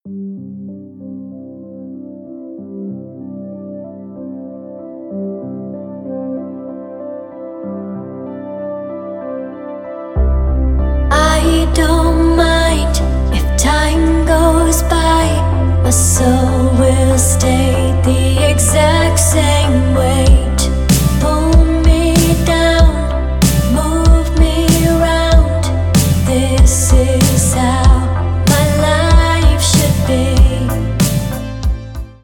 26.06.2020 || Synthwave, Retrowave, Remixes